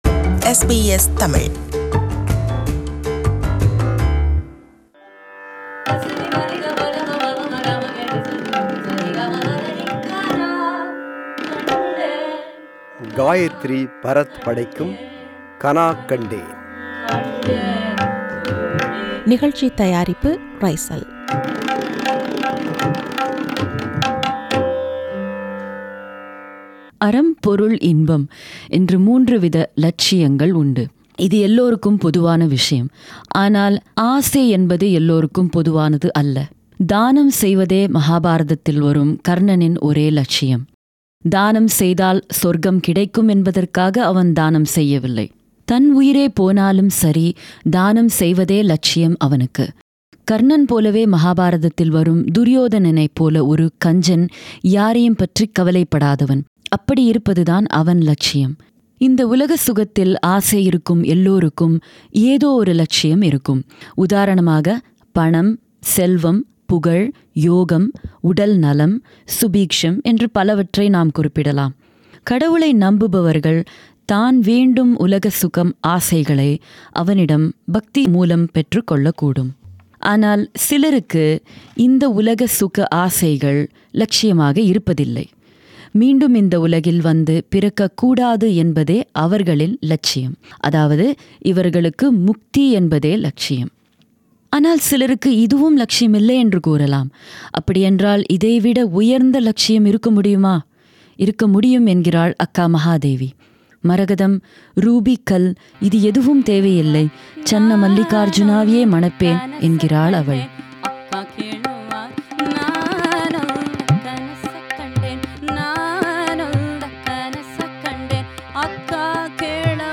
தெய்வீகத்தை, மறைபொருளைத் தேடிய ஒன்பது பெண்களின் வாழ்க்கையையும், அவர்களின் பாடல்களையும் (mystic women) பாடி, விவரிக்கும் தொடர் இது.
Harmonium
Tabla
Mridangam
Tanpura
Studio: SBS